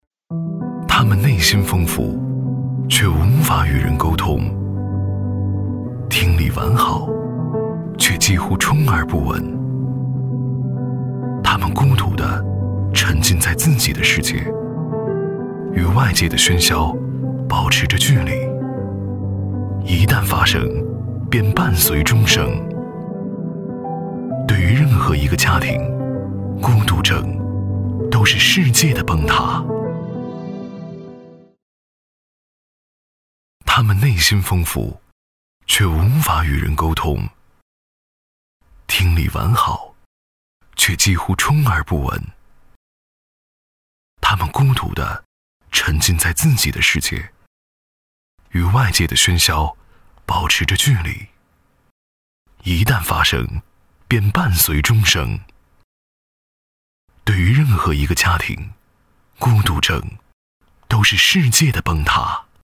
专注高端配音，拒绝ai合成声音，高端真人配音认准传音配音
男47